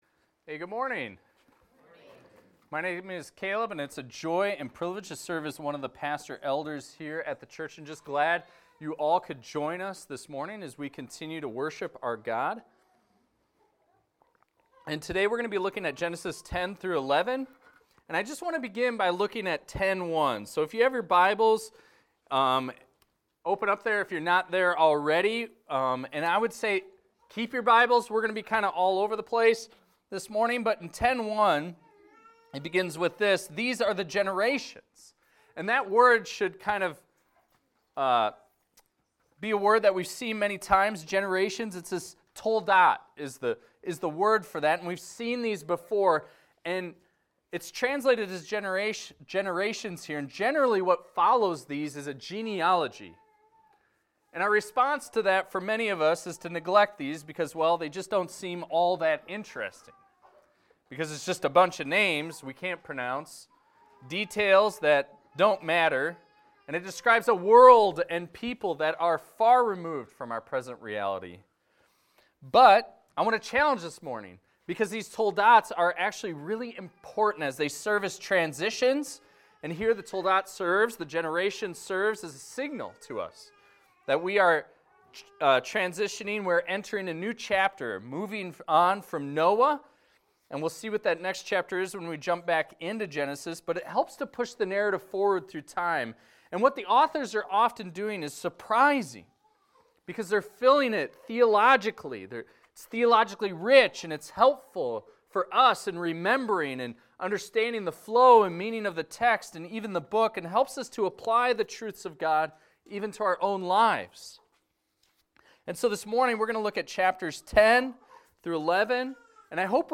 This is a sermon on Genesis 10:1-11:9 titled, "Come Down to the Tower" talking about God's love in confusing the languages and spreading people out.